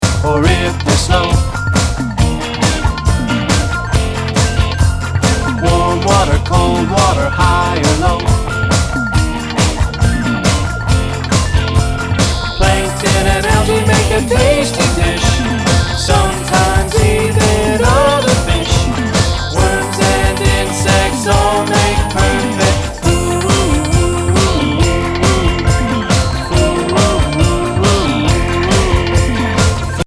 Tuneful and bubbly